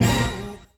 RAP ORCHHIT2.wav